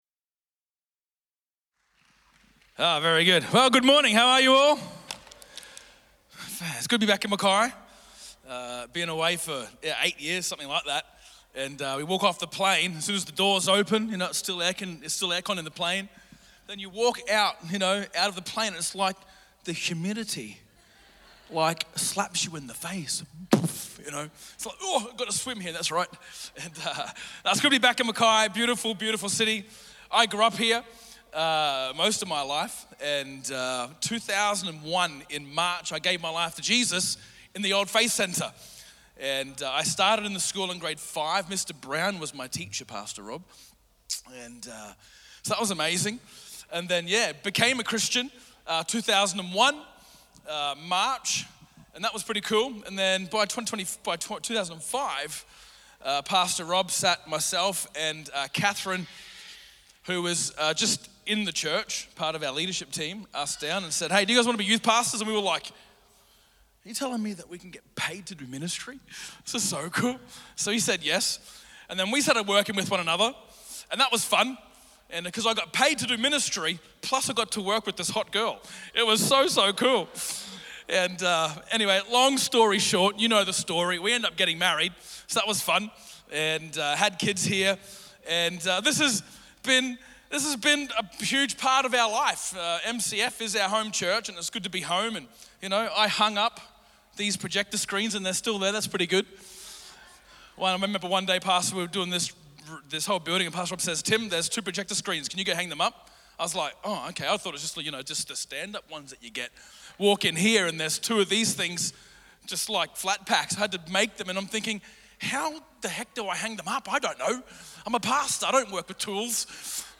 Sermons | Mackay Christian Family